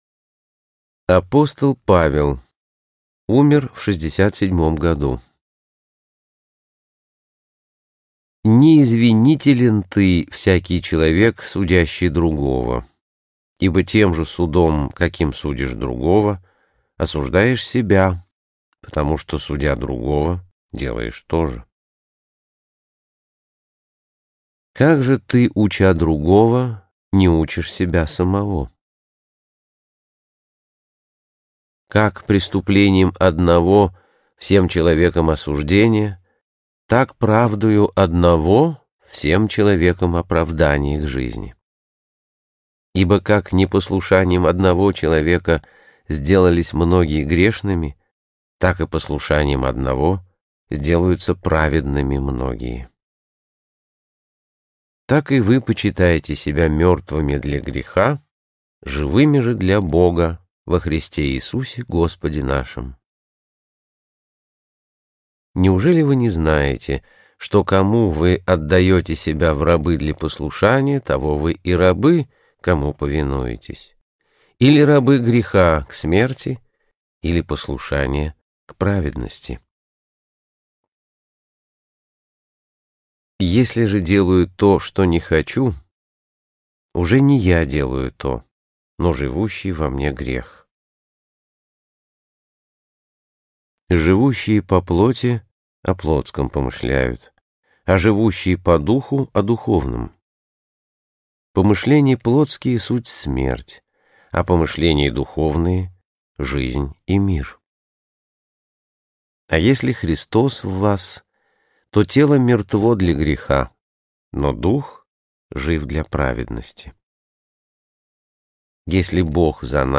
Аудиокнига Мудрость тысячелетий. Христианские изречения, притчи, афоризмы | Библиотека аудиокниг